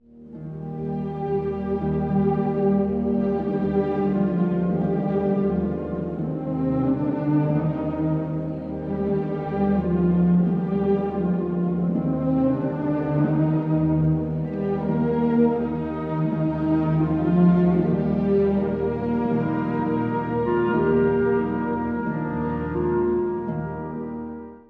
この楽章は、温かさと神秘性が入り混じった、非常に抒情的な音楽です。
ホルンや木管によって何度も歌い直されながら、少しずつ変容していきます。
中間部では、どこか不安気な旋律が浮かび上がり、和声も陰りを帯びます。
静かな祈りのように、しっとりと余韻を残して終わります。